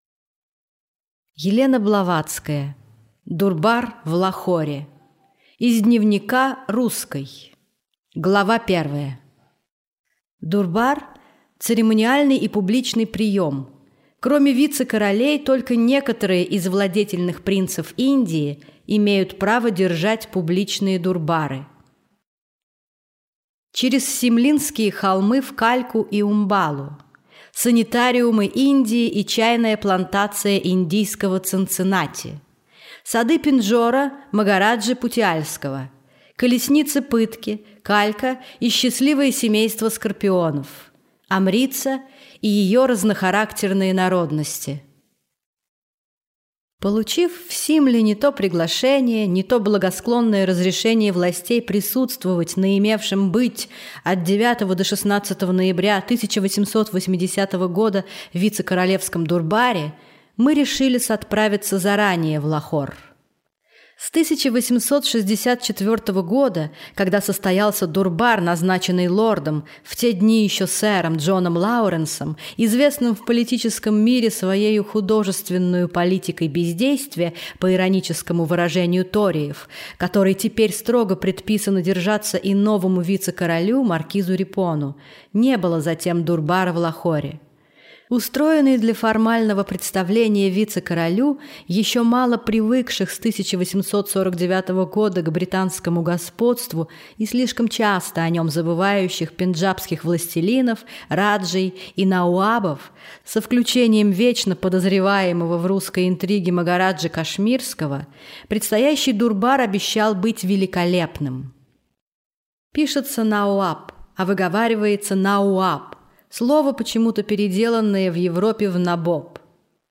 Аудиокнига Дурбар в Лахоре